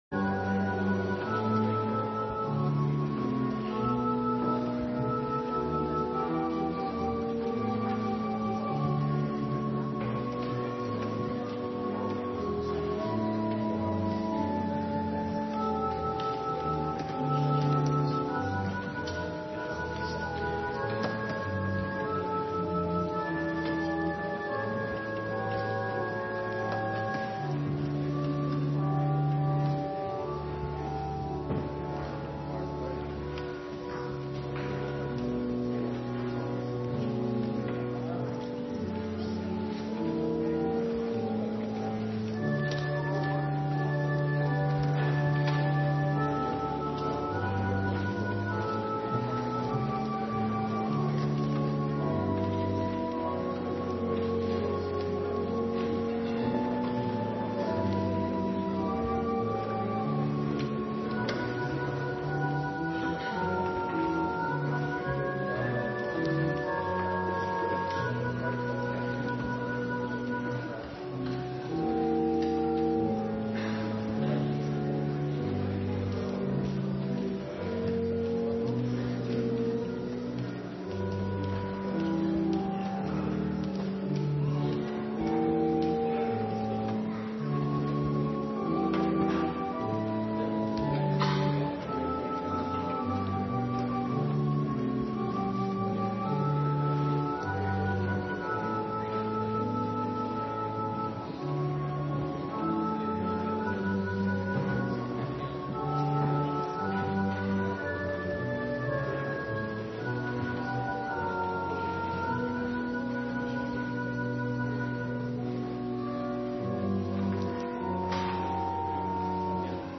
Inleidend orgelspel
Uitleidend orgelspel